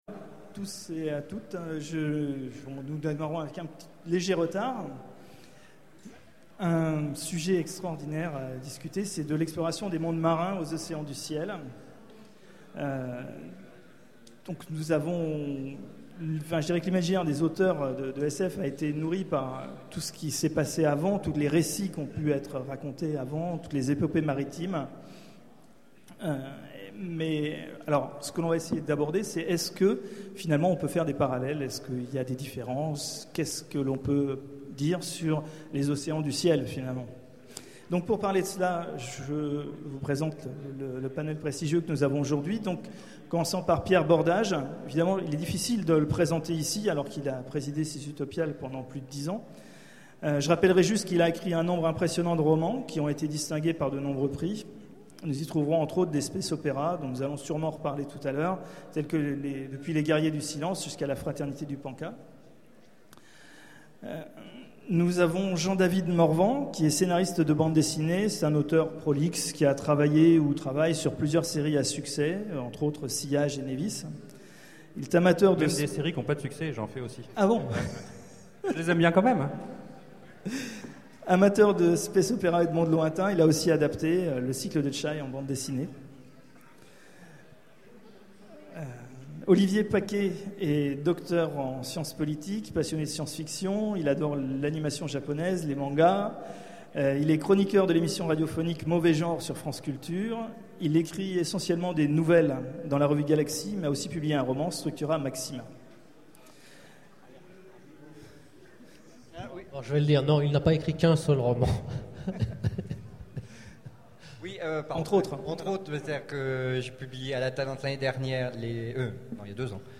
Utopiales 12 : Conférence De l’exploration des mondes marins aux océans du ciel